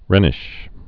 (rĕnĭsh)